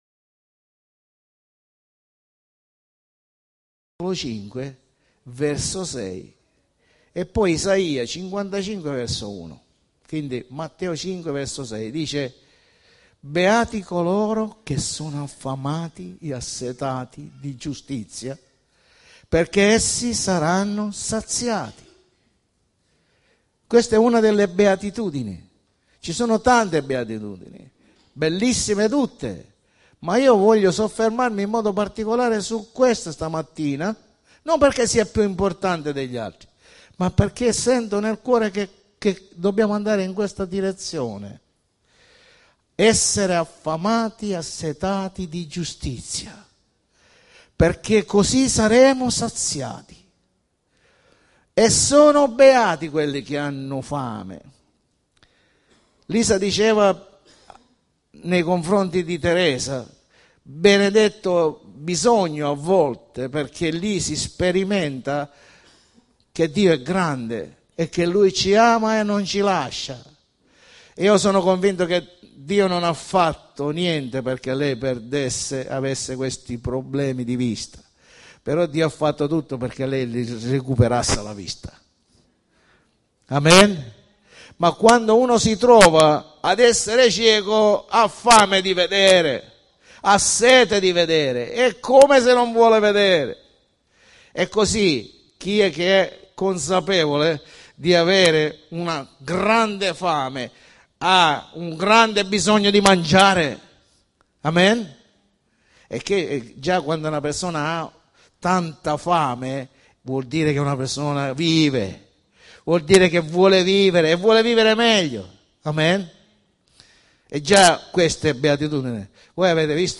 Pred.